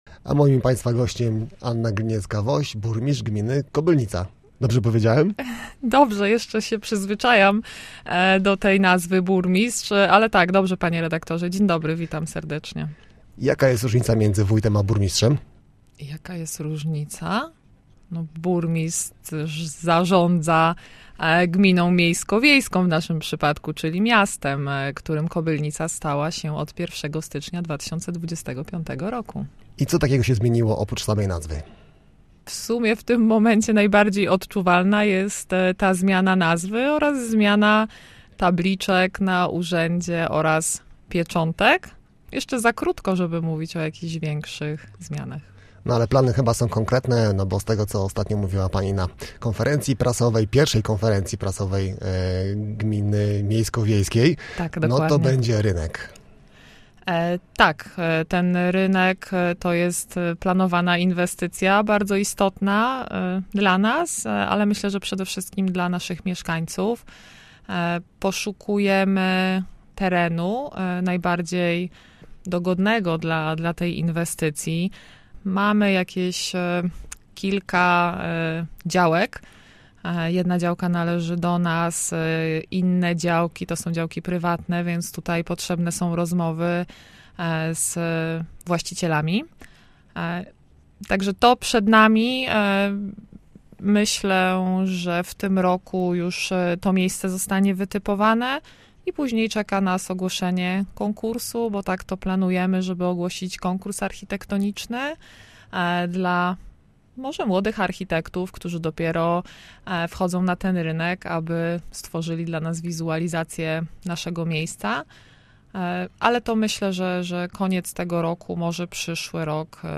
Posłuchaj rozmowy z Anną Gliniecką-Woś, burmistrzem Kobylnicy:
Kobylnica_burmistrz_14_33.mp3